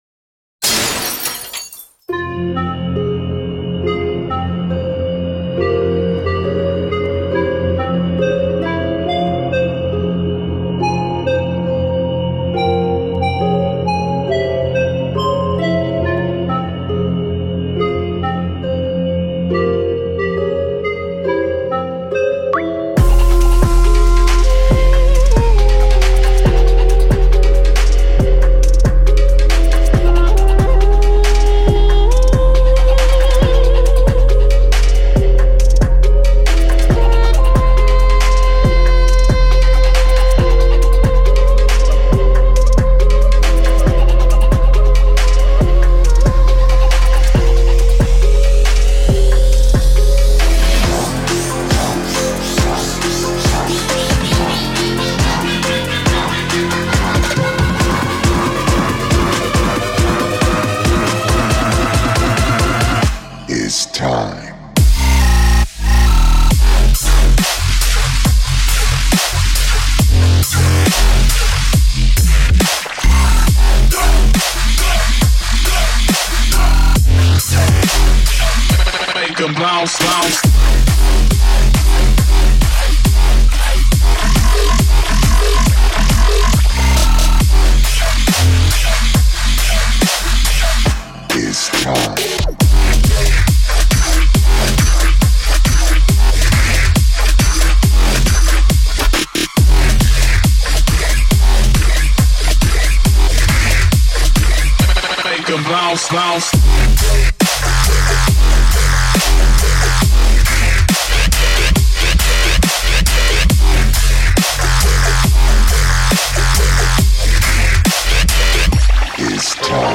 規定時間3分に収めるためにいくつか編集箇所があります。